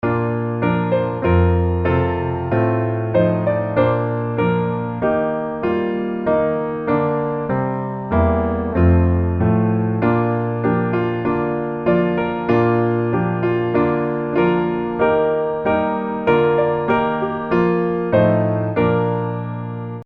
降B大調